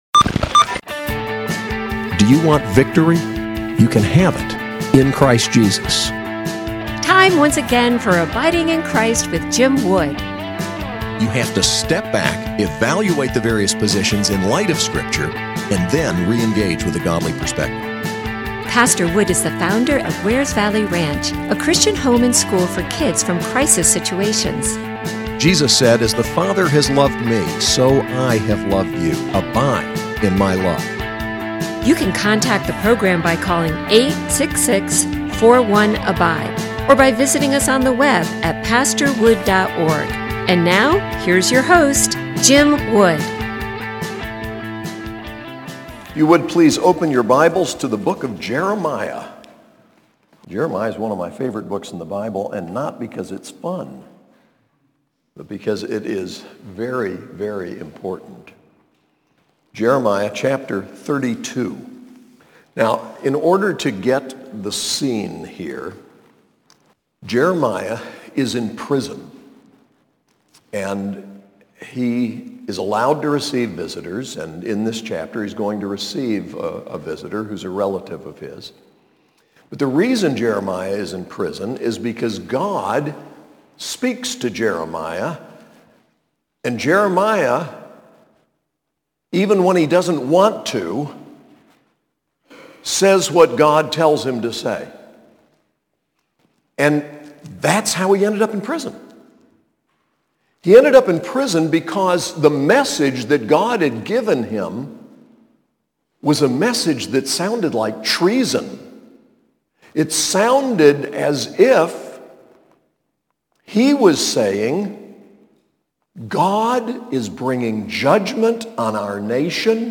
SAS Chapel: Jeremiah 32:1-27